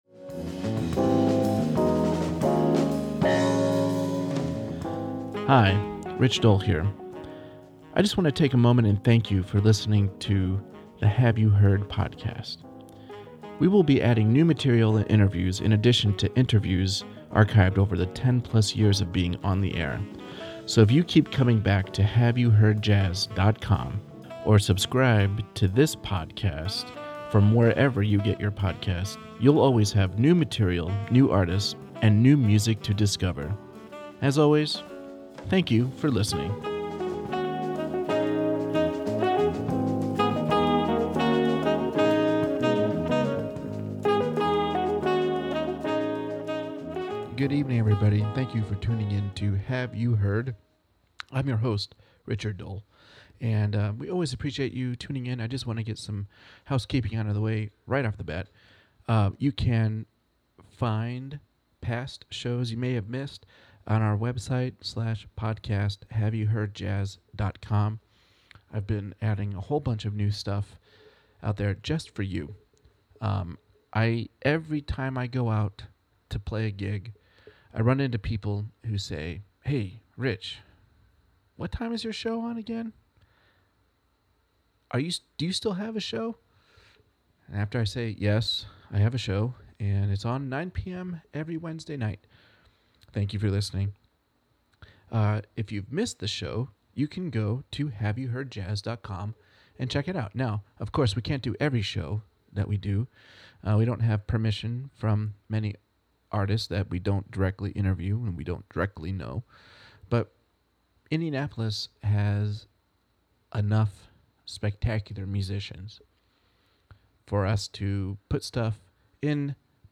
I hope you enjoy the music and conversation!